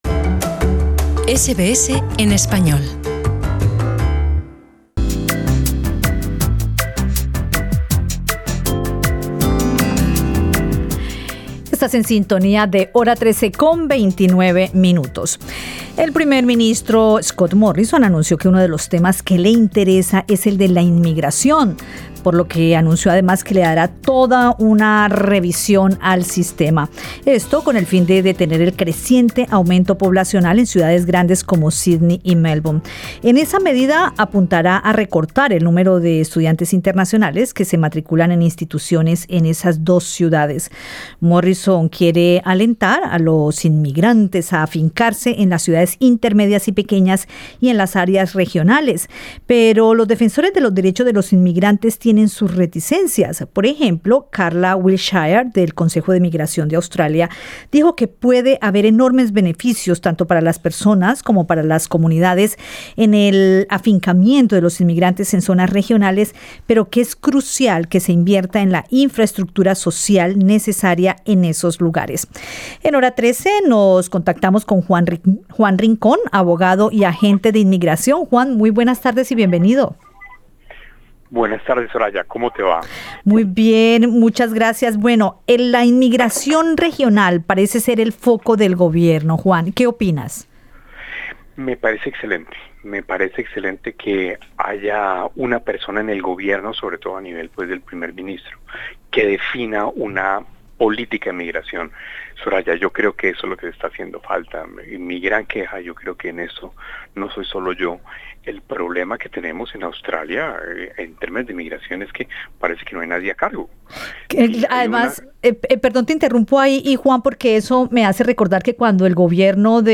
El gobierno australiano busca incentivar la migración a las ciudades intermedias y pequeñas y al interior del país. Conversamos con el abogado y agente de inmigración